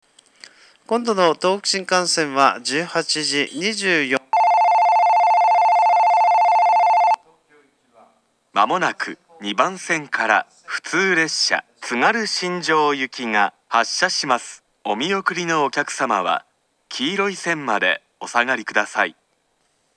ホーム上には黒いスピーカーと白いスピーカーの2種類がありますが、自動放送・発車ベルが流れるのは白い色のほうです。
接近放送
普通列車津軽新城行きの接近放送です。